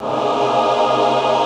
CHOIR 1.wav